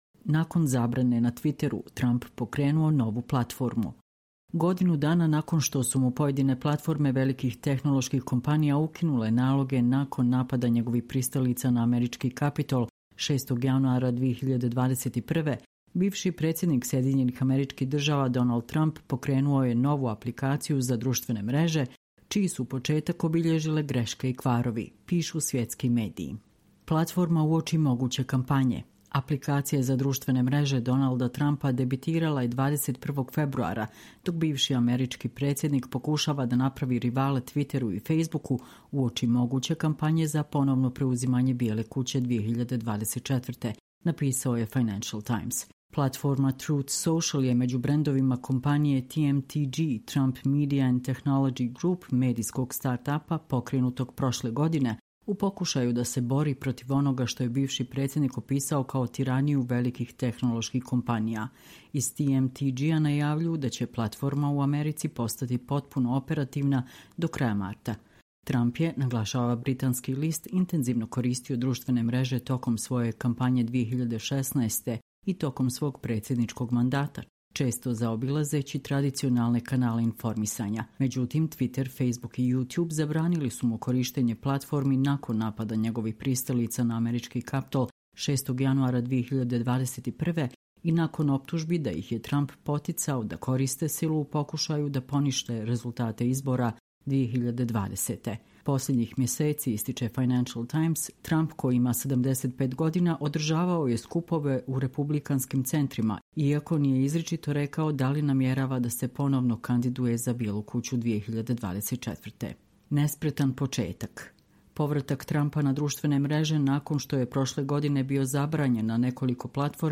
Čitamo vam: Nakon zabrane na Twitteru, Trump pokrenuo novu platformu